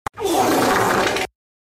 Fart.mp3